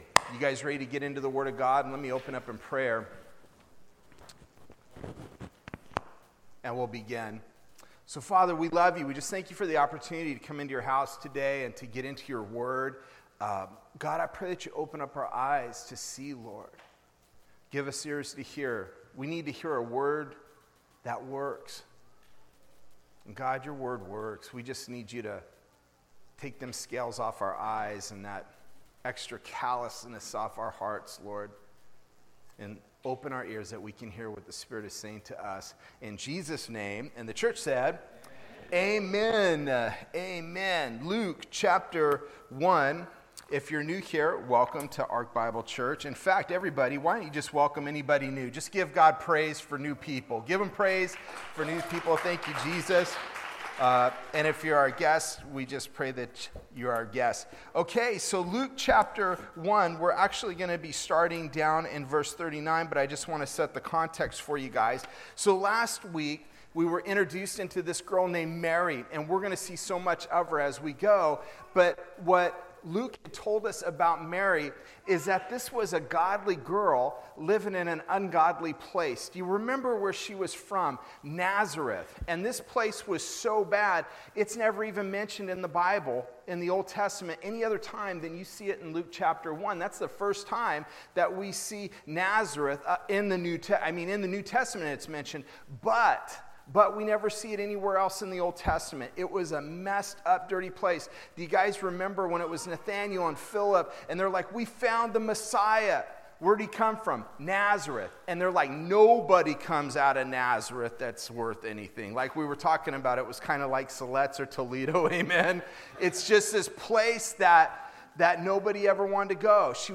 Sermons Archive - Page 22 of 44 - Ark Bible Church